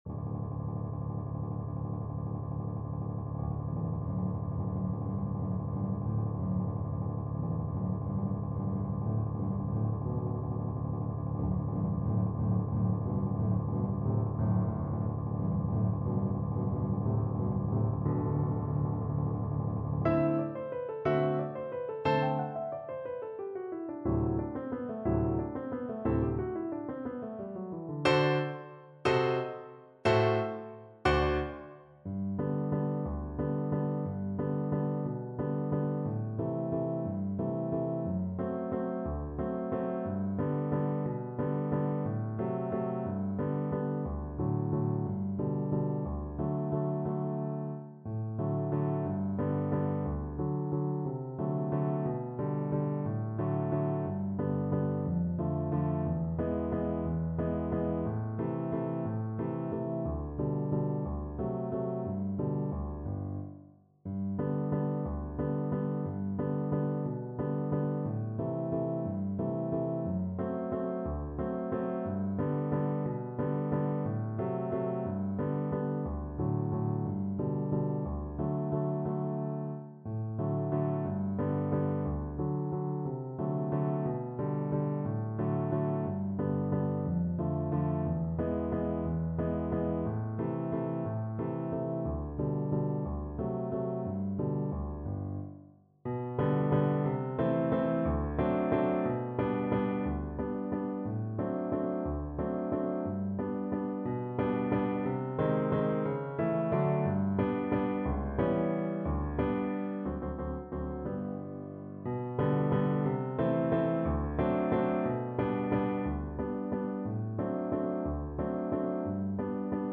Free Sheet music for Violin
Violin
G major (Sounding Pitch) (View more G major Music for Violin )
Tempo di Valse .=c.60
3/4 (View more 3/4 Music)
G4-B6
Classical (View more Classical Violin Music)